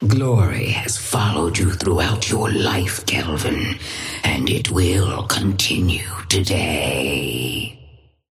Sapphire Flame voice line - Glory has followed you throughout your life, Kelvin, and it will continue today.
Patron_female_ally_kelvin_start_05.mp3